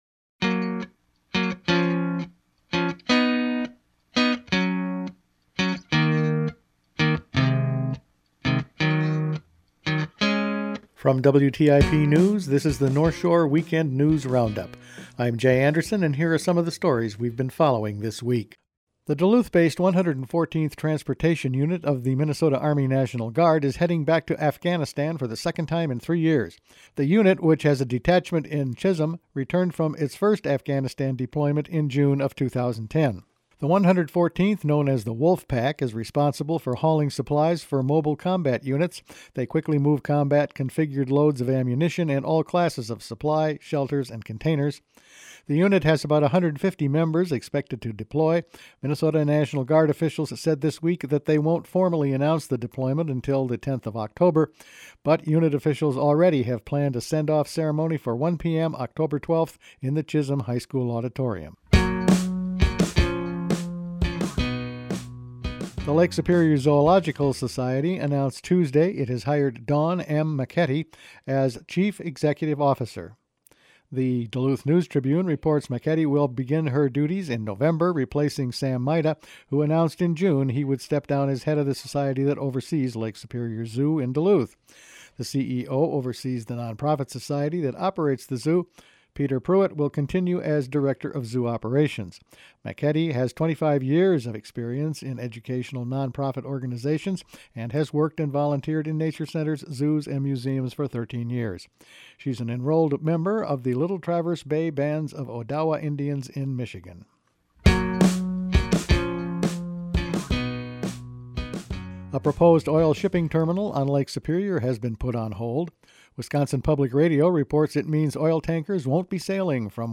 Each week the WTIP news staff puts together a roundup of the news over the past five days. Moose calf mortality is up, new habitat lands proposed for lynx, the 144th will deploy to the Middle East, again, and much more…all in this week’s news.